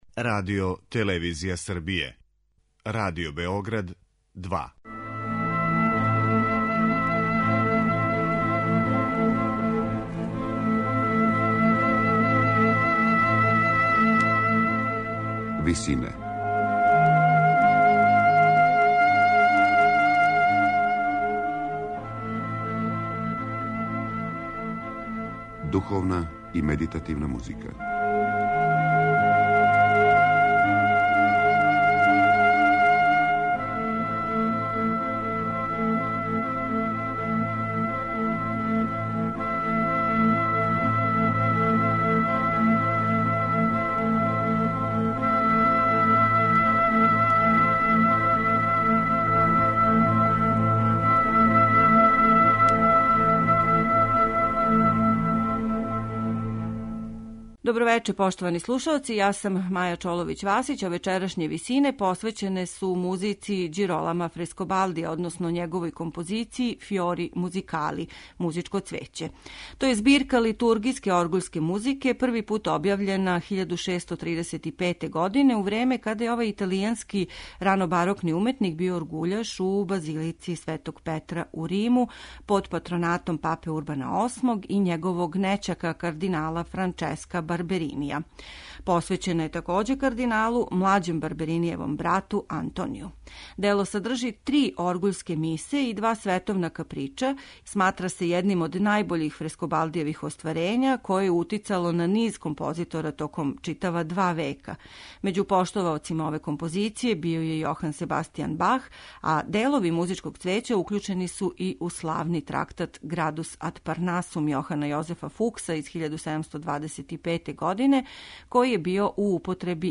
Дело које чине три оргуљске мисе овог ранобарокног италијанског композитора имало је утицаја на низ композитора током готово два века након објављивања 1635. године.